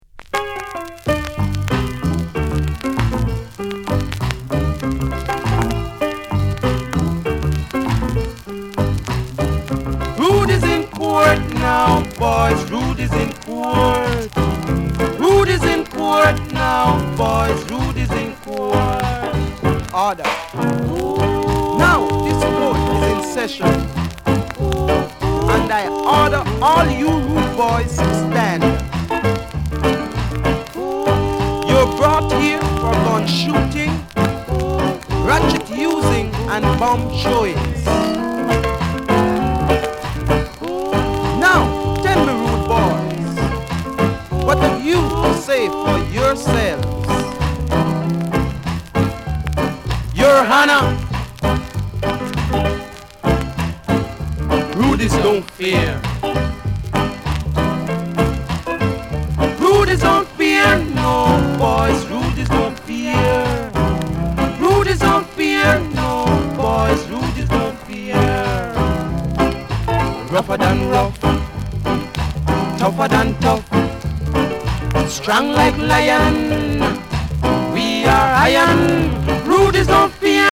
Genre: Rocksteady